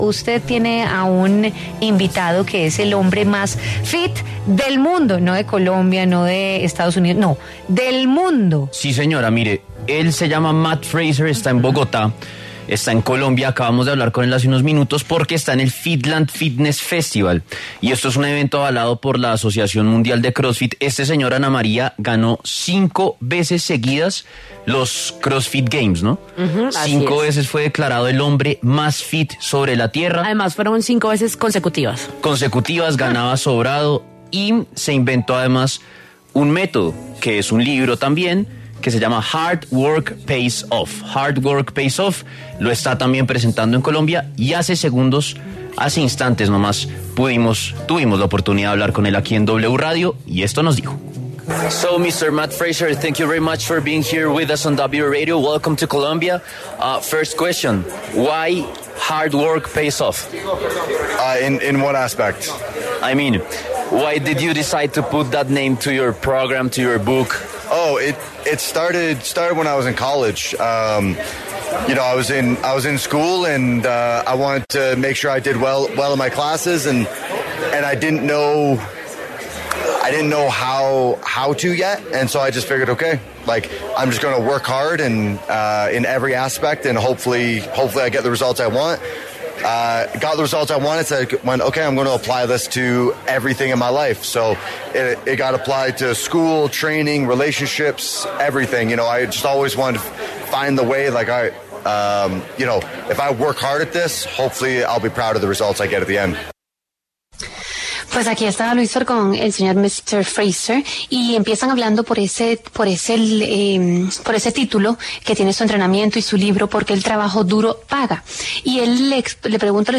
El reconocido deportista Mat Fraser, quien es considerado el hombre más fit del mundo, pasó por los micrófonos de La W para hablar sobre su carrera deportiva y los nuevos proyectos que tiene.